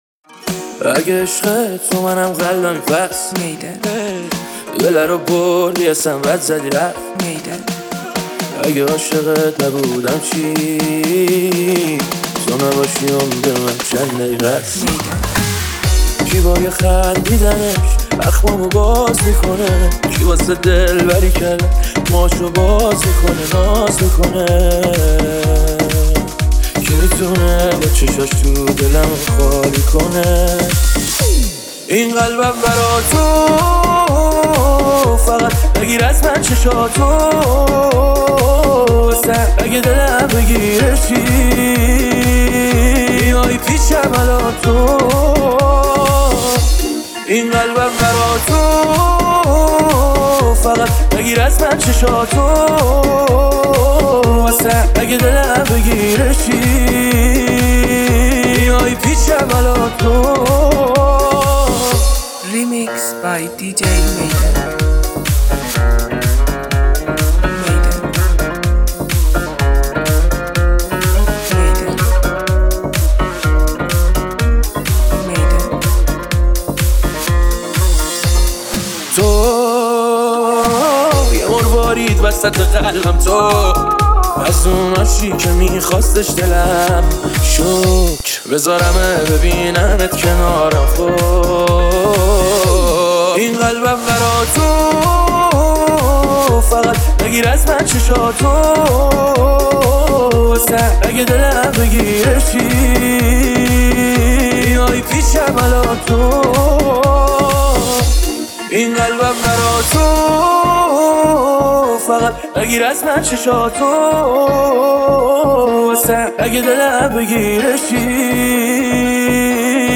تند بیس دار